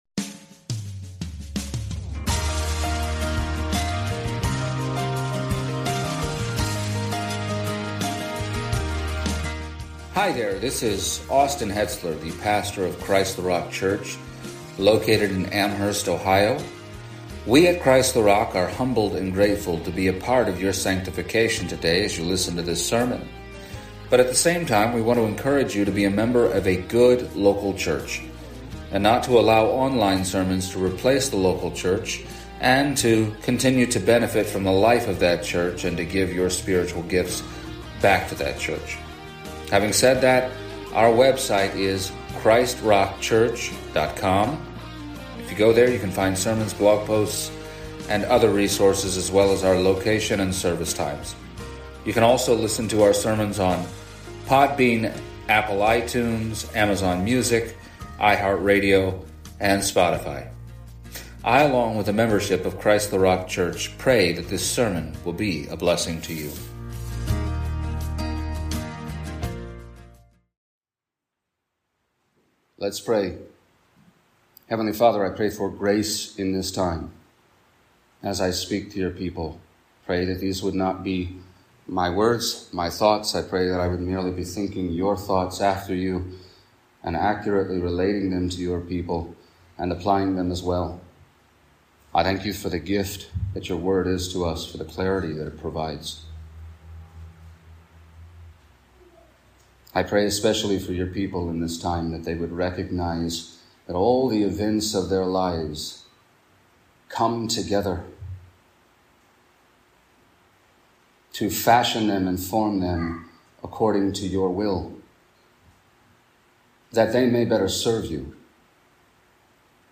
Acts 20:25-32 Service Type: Sunday Morning Regret is an aspect of repentance.